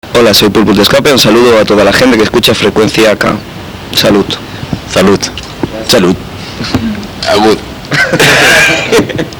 13/11/01: Après le concert de Ska-p à Toulon: Rencontre et interview avec le groupe.